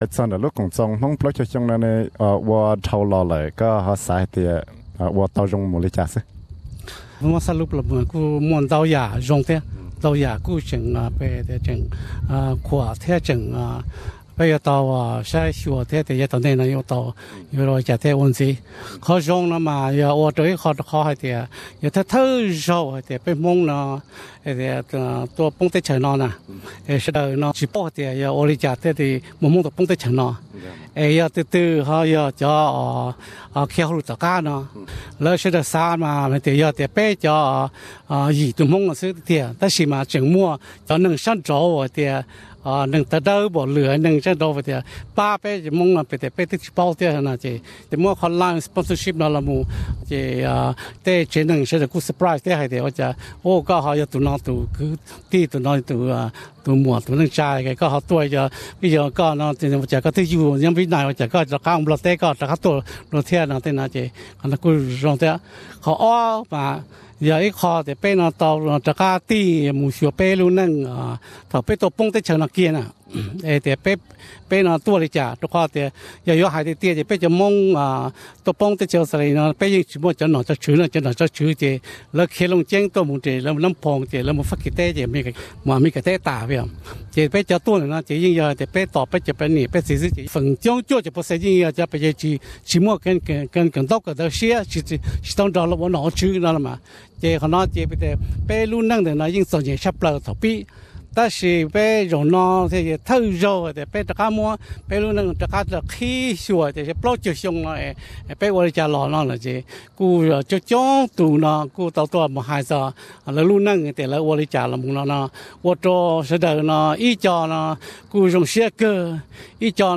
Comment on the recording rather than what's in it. VOX reaction from Hmong Australia celebrating 40 years Source: Hmong Australia Festival Inc